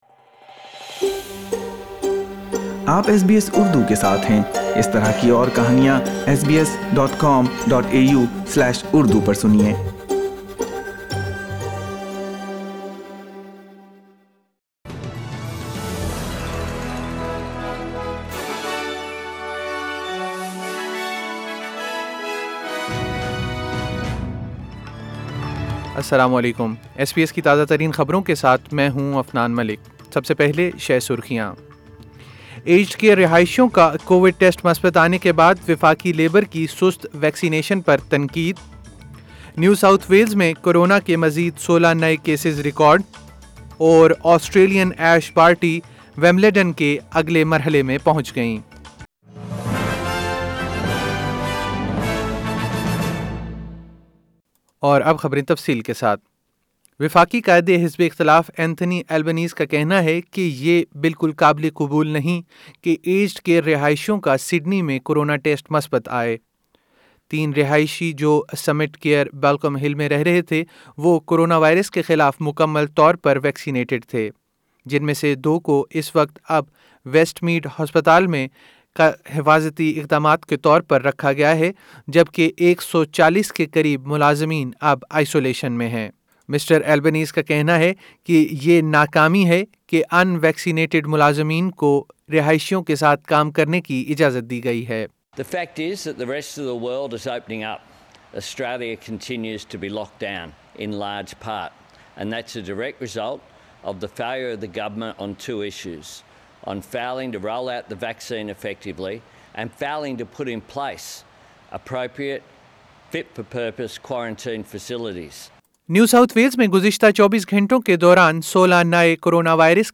SBS Urdu news 04 July 2021